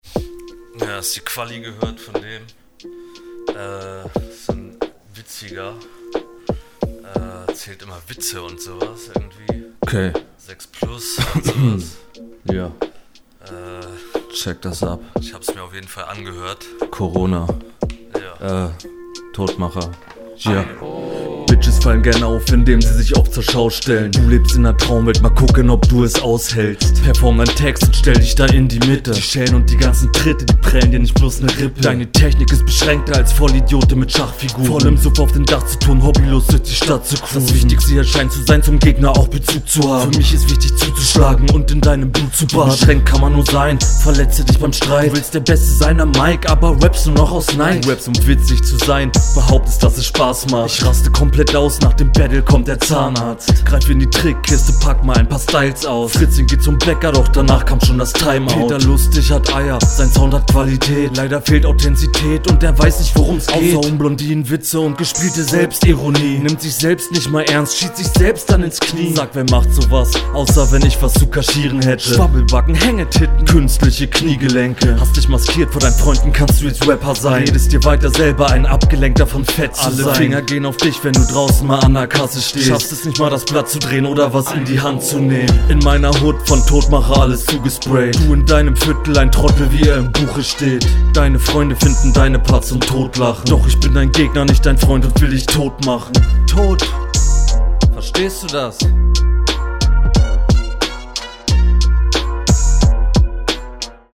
Ich finde dich hier sehr verhalten was deinen Flow und die Stimme betrifft.